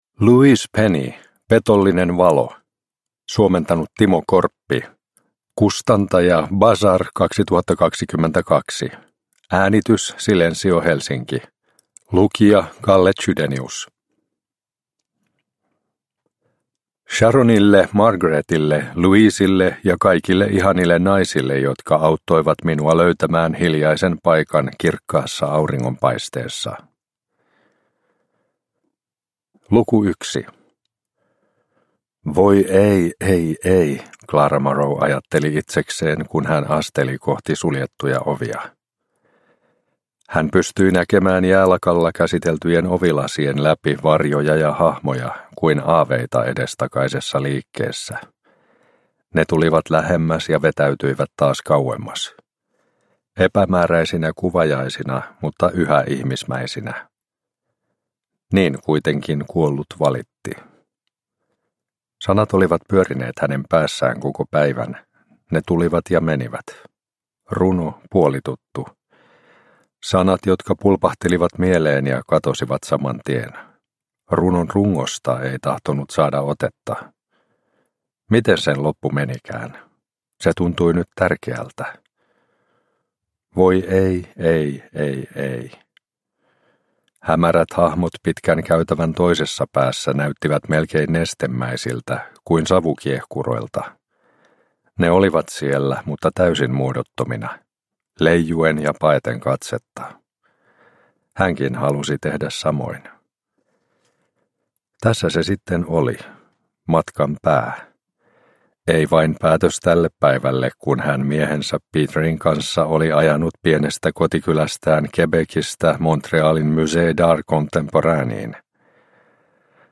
Petollinen valo – Ljudbok